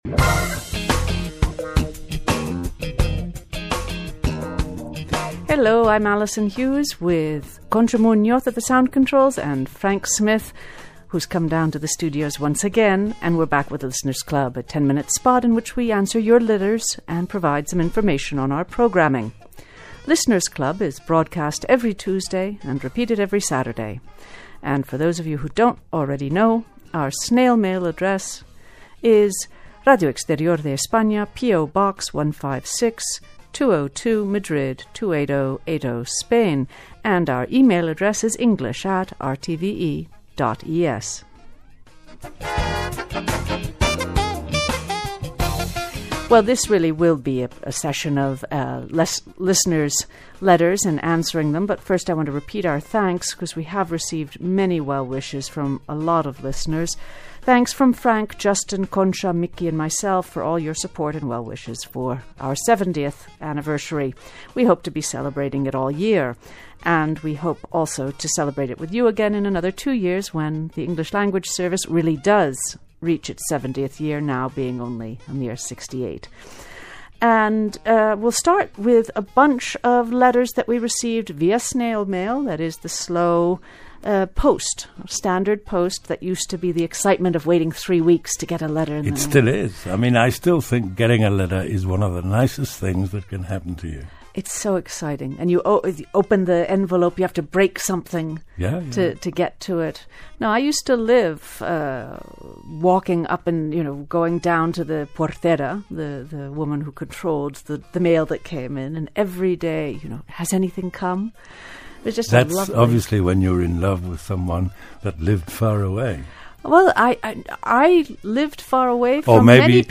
Here you will find two listening lessons based on the same snippets from an English Language Broadcast on the Spanish National Radio station.